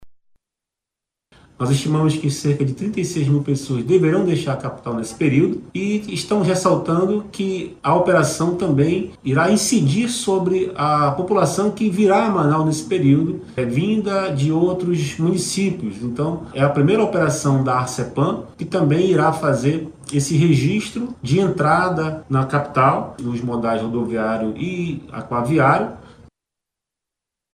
A estimativa da Agência Reguladora de Serviços Públicos Delegados e Contratados do Estado do Amazonas (Arsepam) é de que mais de 36 mil pessoas devem utilizar os transportes intermunicipais, tanto o rodoviário como o hidroviário, com a finalidade de acessar as mais diversas cidades do interior do estado, como explica o diretor-presidente da Arsepam, João Rufino Júnior.
Sonora-Joao-Rufino-Junior-diretor-presidente-da-Arsepam.mp3